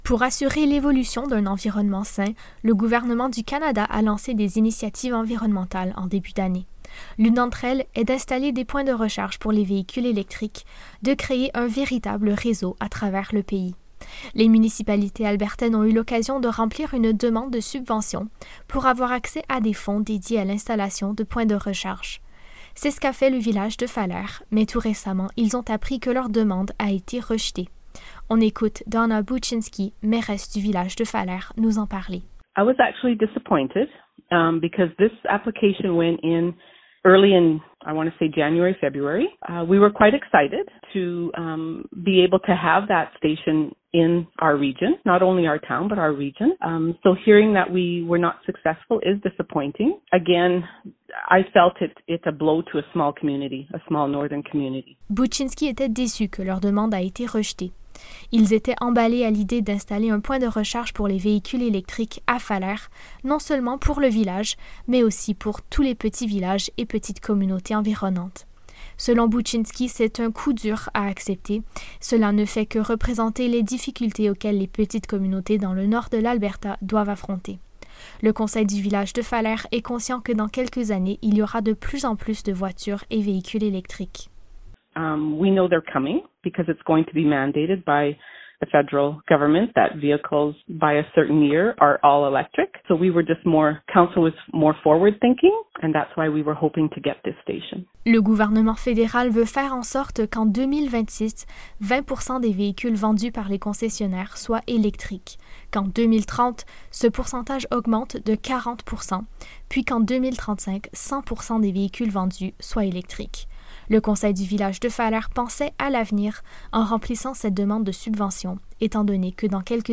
Les réactions de la mairesse de Falher, Donna Buchinski :